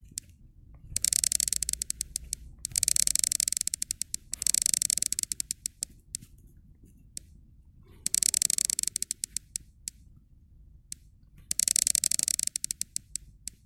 Звук перемотки даты или цифр в iPhone